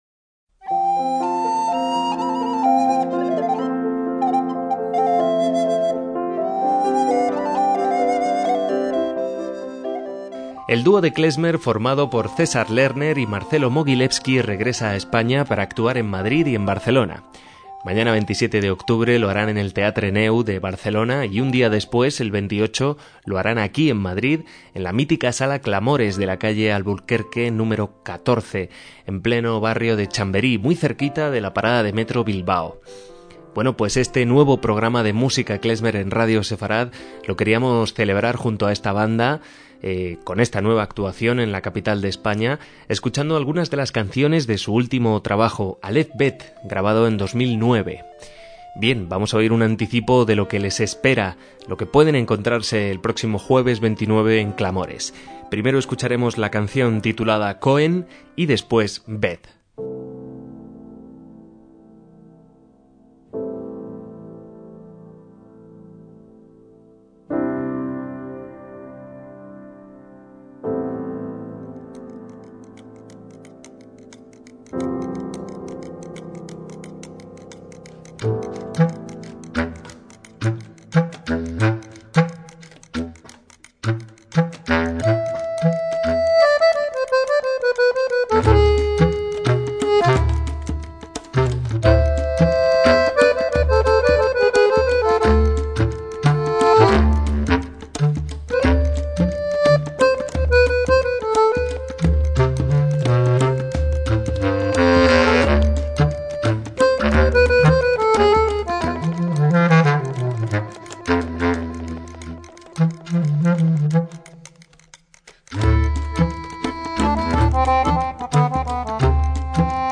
MÚSICA KLEZMER
tecladista
vientista